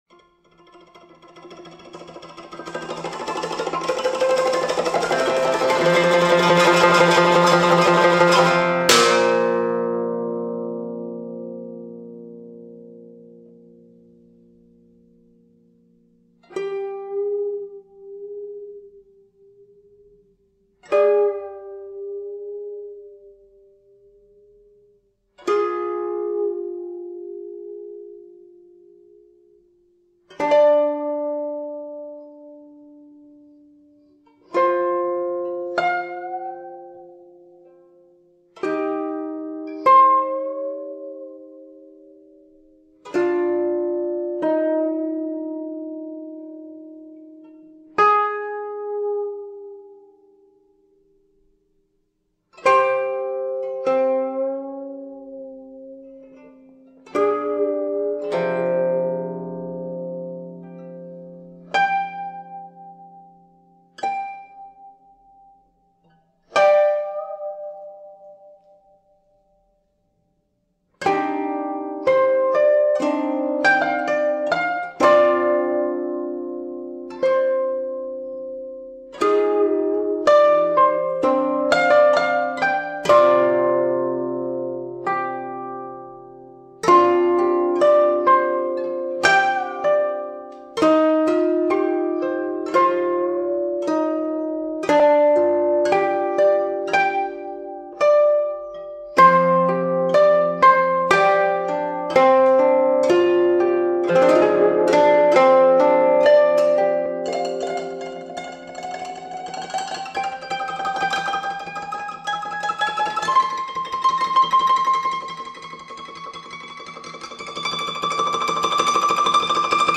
Prière d’hiver exaucée par les notes sereines d’un koto